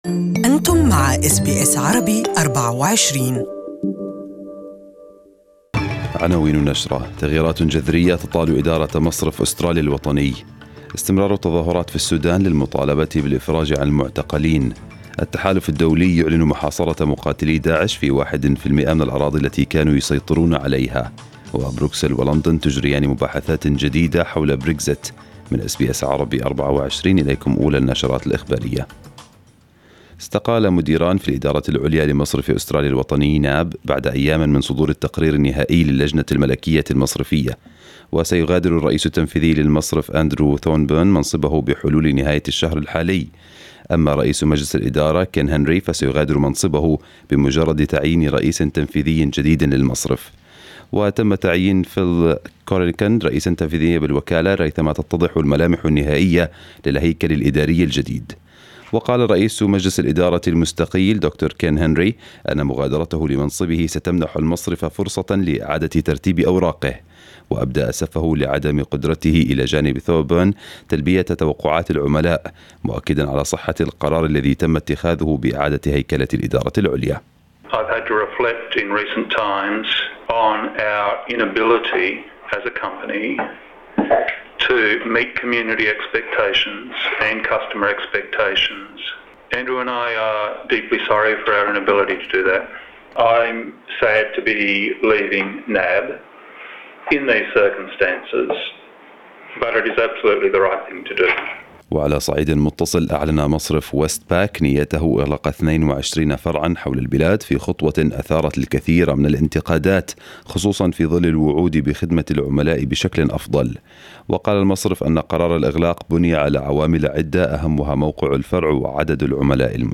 News bulletin of the day in Arabic